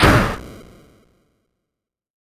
hostExplode.ogg